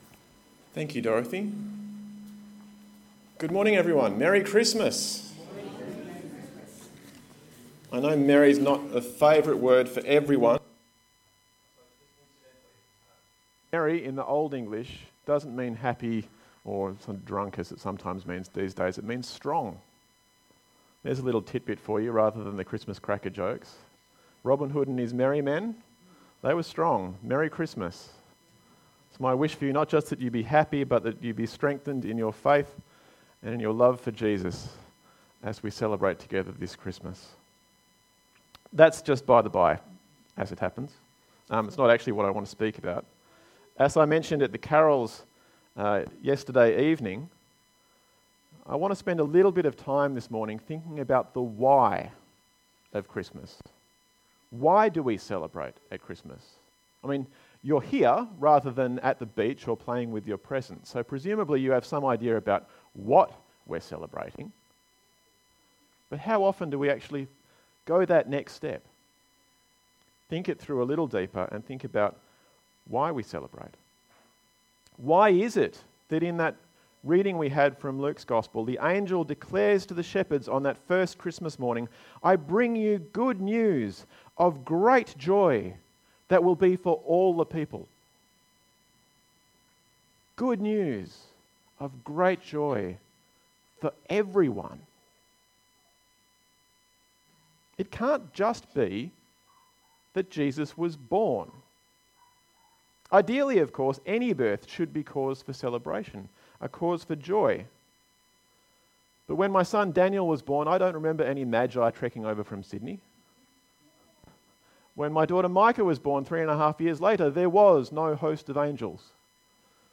Various Service Type: Sunday AM Bible Text